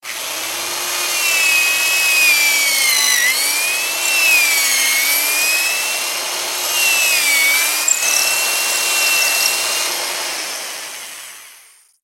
Все треки четкие и реалистичные.
Дрель - Модификация 2